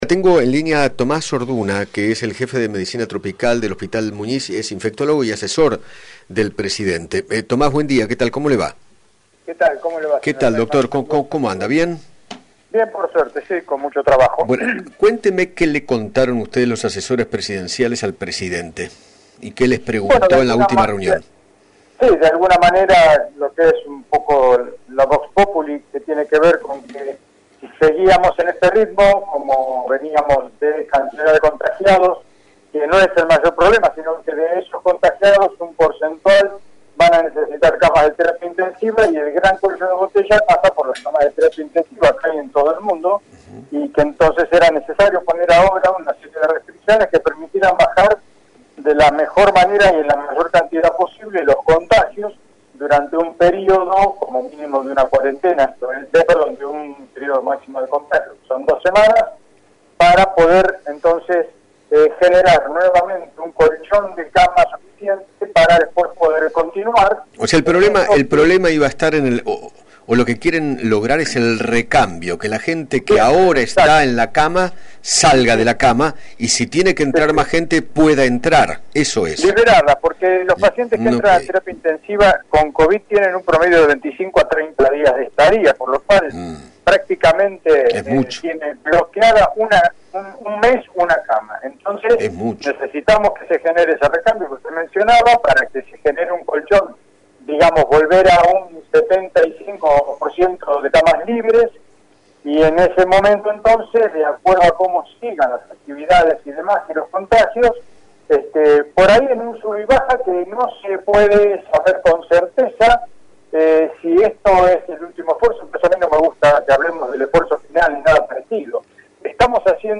dialogó con Eduardo Feinmann sobre la reunión que mantuvo en el día de ayer con Alberto Fernández junto a los demás especialistas de la salud para definir las nuevas medidas que regirán a partir del 1 de julio.